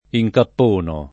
incappono [ i j kapp 1 no ]